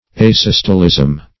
Asystolism \A*sys"to*lism\, n.